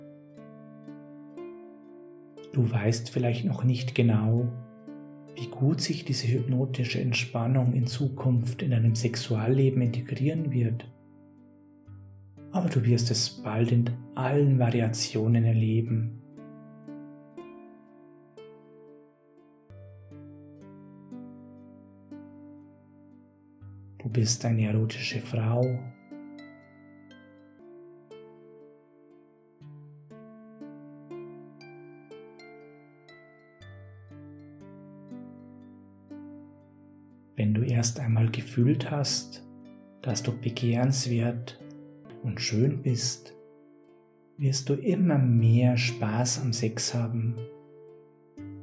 Mit unserer speziellen geführten Hypnose fördern wir die Spaß und Lust am Sex mit Ihrem Partner, geben Ihnen Sicherheit und nehmen Ihnen jeglichen Erfolgsdruck!